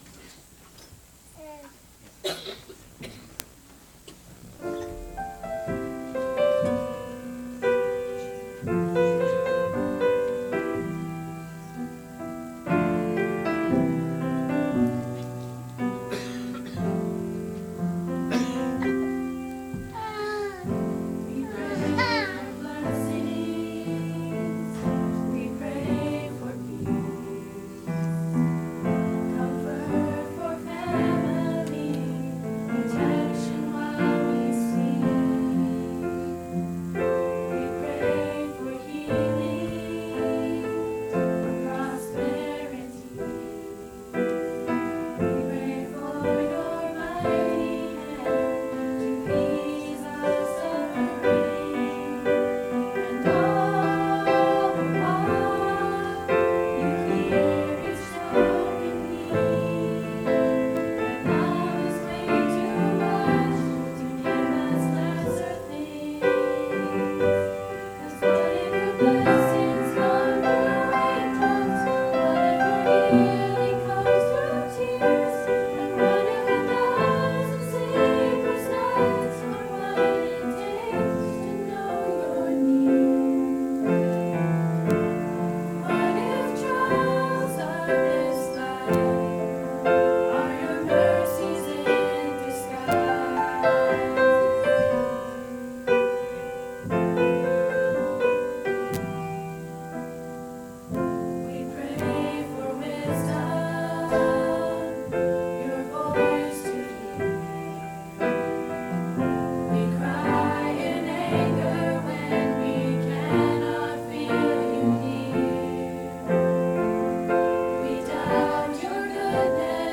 Sunday Feb 26 Young People Songs
Shepherds Christian Centre Convention